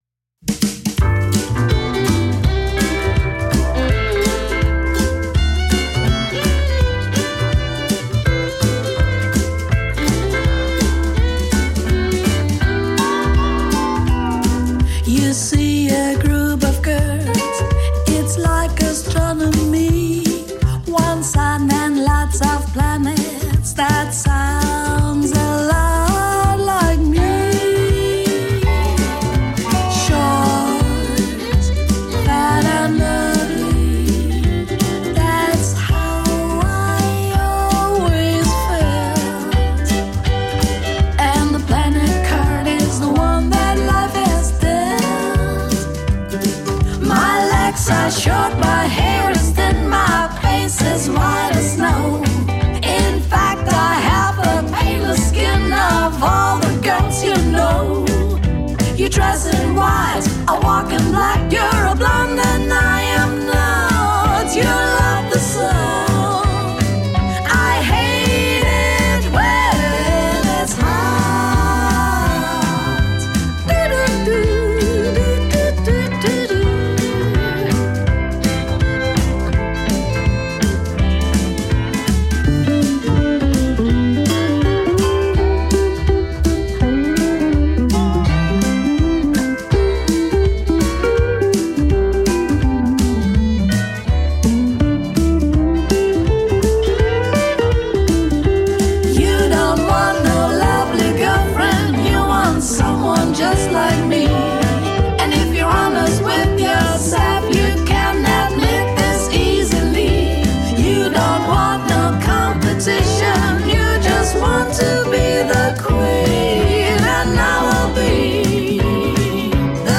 German singer/songwriter/producer